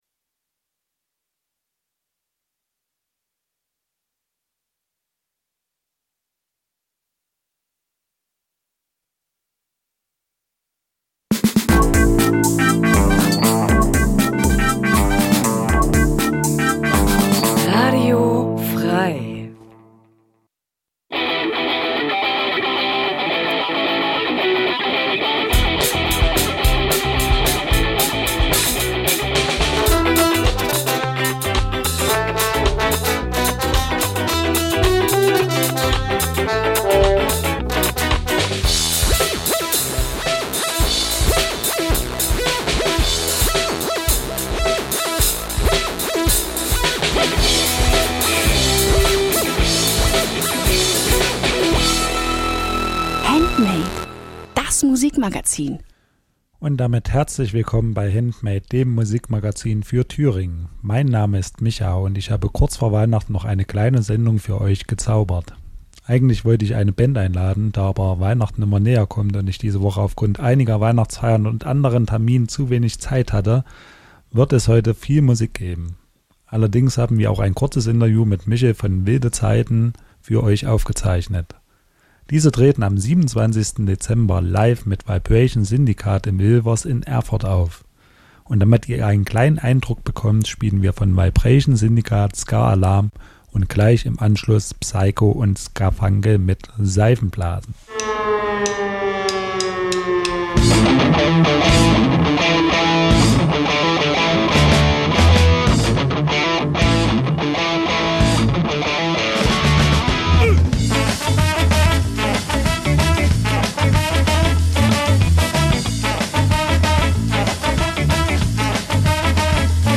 Jeden Donnerstag stellen wir euch regionale Musik vor und scheren uns dabei nicht um Genregrenzen.
Wir laden Bands live ins Studio von Radio F.R.E.I. ein, treffen sie bei Homesessions oder auf Festivals.